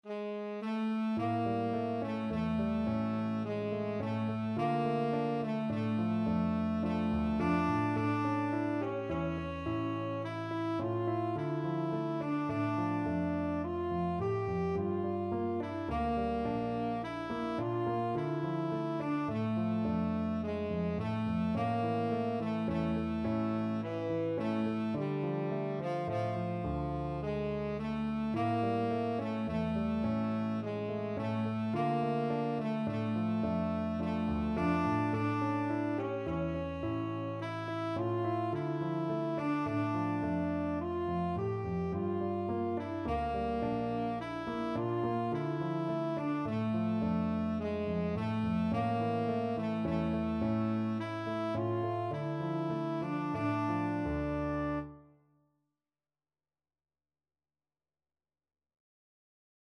World Asia Russia
Slow Waltz = c. 106
3/4 (View more 3/4 Music)